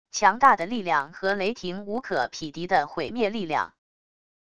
强大的力量和雷霆无可匹敌的毁灭力量wav音频